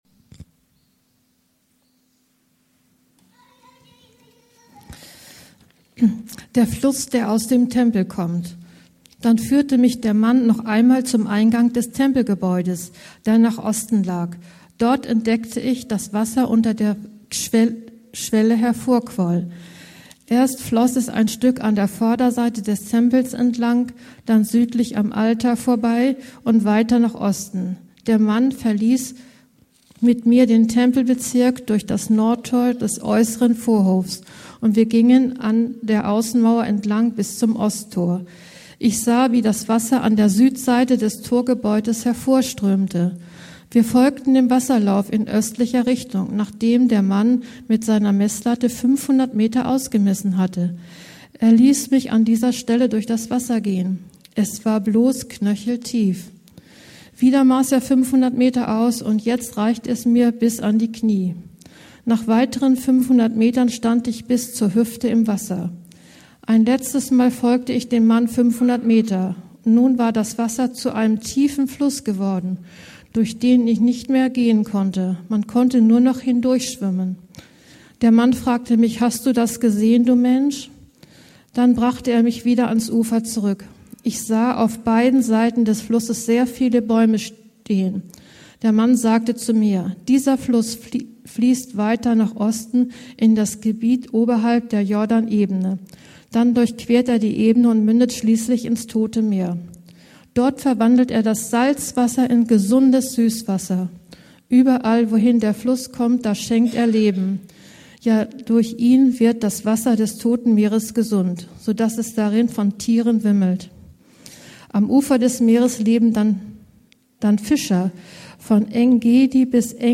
Schwimmen im Strom Gottes - Hesekiel 47, 1-12 ~ Anskar-Kirche Hamburg- Predigten Podcast